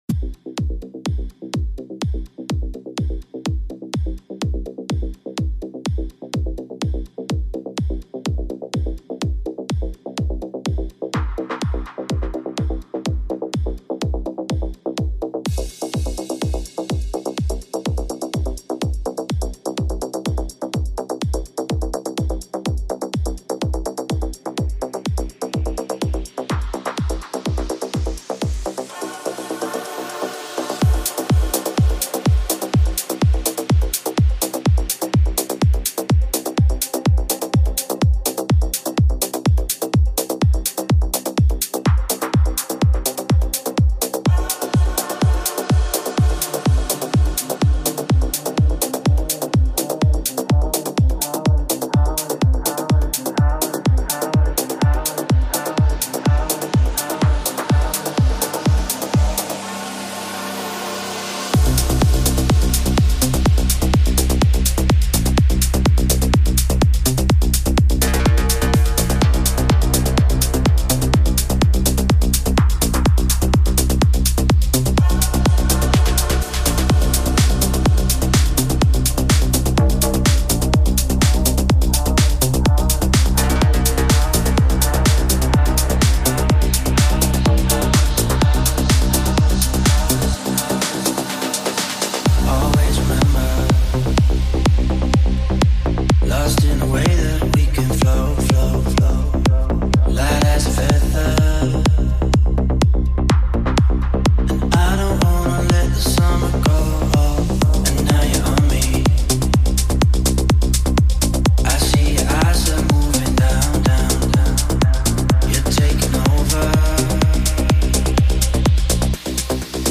Top 40 • Club Music • House • NuDisco • Progressive Sounds